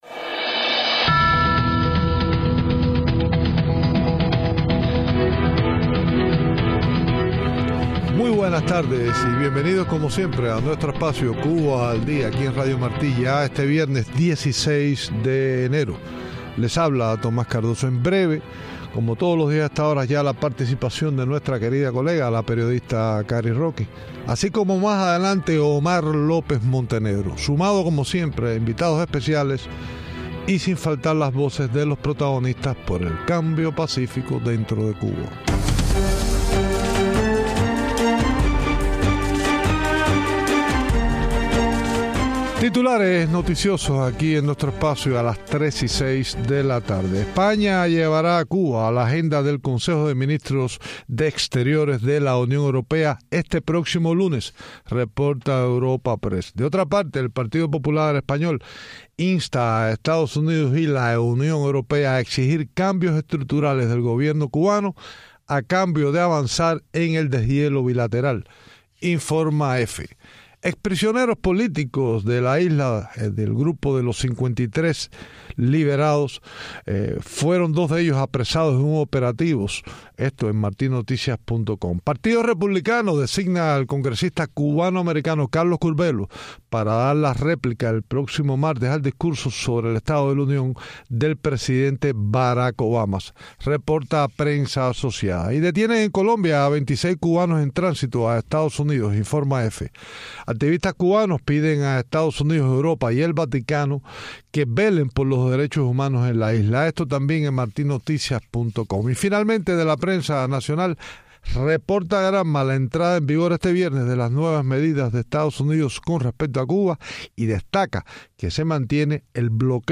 Entrevistas
ambas iban a dar su opinión sobre las futuras relaciones entre EU- Cuba, la llamada se interrumpió en varias ocasiones hasta perder el contacto